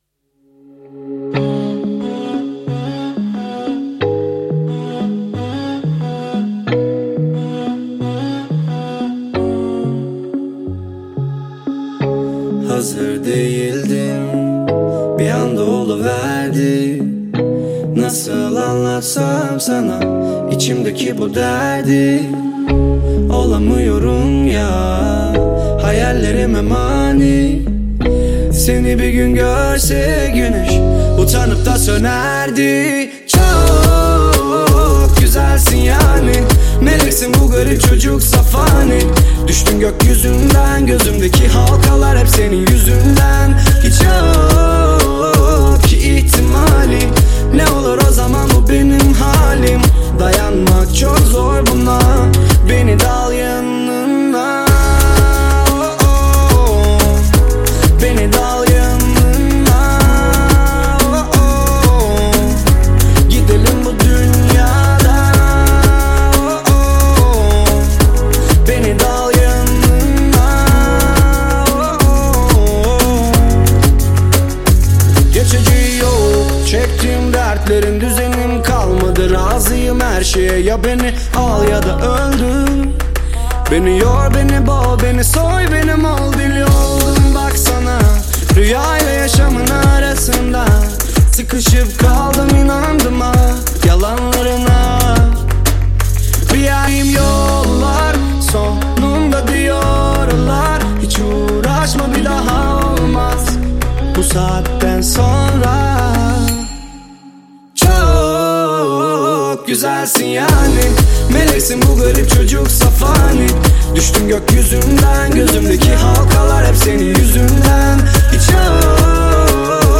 ژانر: پاپ ، رپ